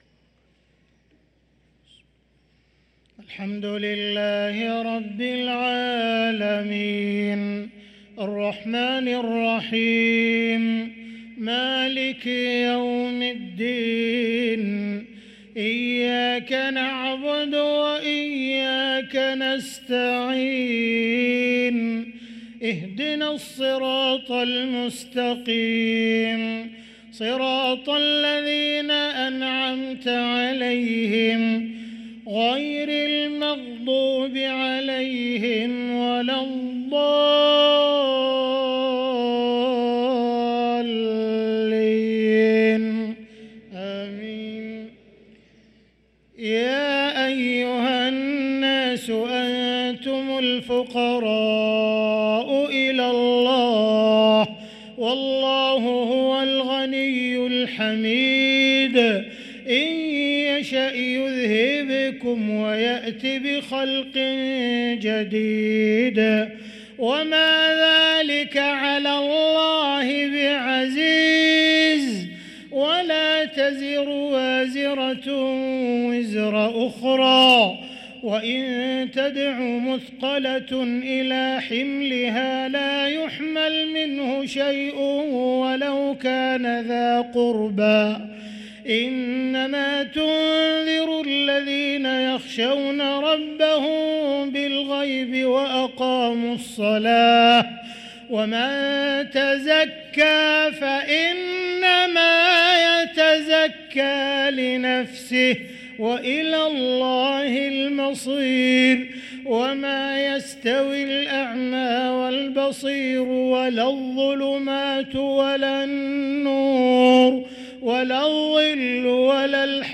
صلاة العشاء للقارئ عبدالرحمن السديس 23 جمادي الأول 1445 هـ
تِلَاوَات الْحَرَمَيْن .